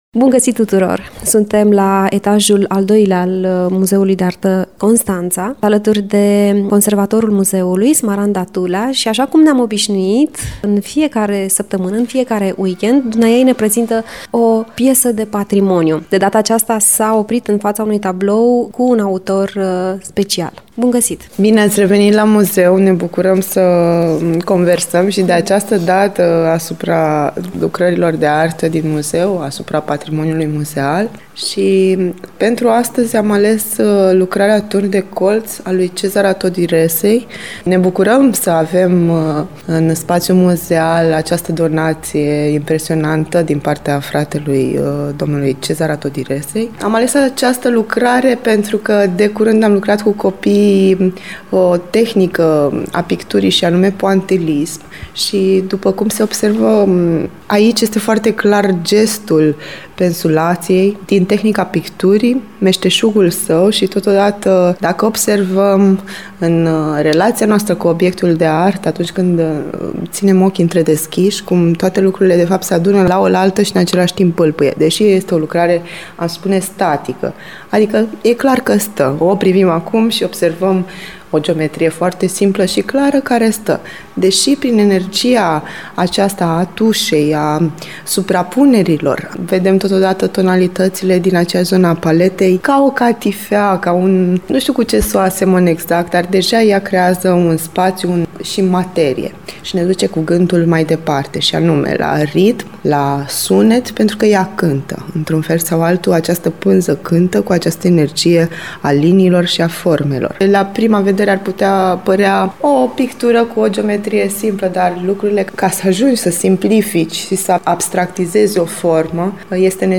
Despre semnificațiile acestei creații ne vorbește conservatorul